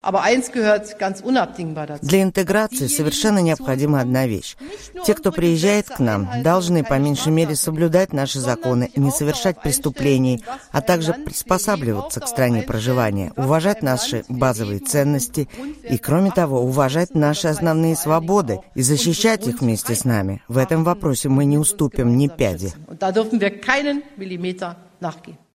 Заявление Меркель прозвучало в субботу на встрече с соратниками в Мекленбурге –Передней Померании
Говорит канцлер Германии Ангела Меркель (2)